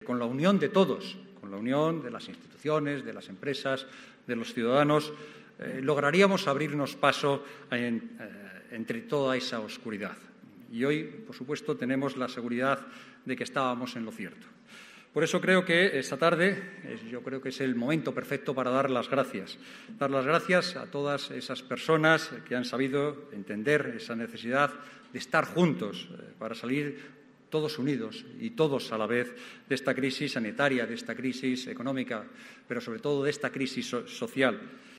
Los Premios Solidarios ONCE Edición Especial 2021Abre Web externa en ventana nueva homenajearon, el pasado 1 de diciembre, la solidaridad y fuerza de la sociedad castellano y leonesa, durante la ceremonia de entrega de galardones que se celebró en el Teatro Zorrilla de Valladolid.
En la clausura de la gala, el presidente de las Cortes de Castilla y León, Luis Fuentes, hizo referencia a la grave situación vivida en los últimos meses a consecuencia de la pandemia y durante los que se ha demostrado que “con la unión de todos, de las instituciones, las empresas, los ciudadanos, lograríamos abrirnos paso entre toda esa oscuridad y esta tarde es el momento perfecto para dar las